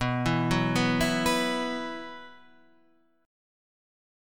B Suspended 4th